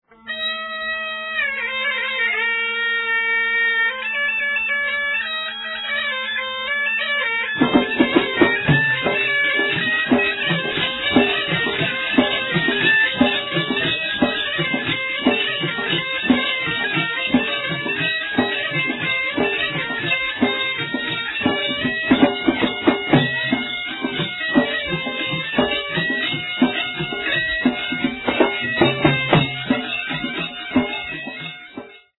Folk Tune (2:49)